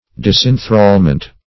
Search Result for " disinthrallment" : The Collaborative International Dictionary of English v.0.48: Disinthrallment \Dis`in*thrall"ment\, n. A releasing from thralldom or slavery; disenthrallment.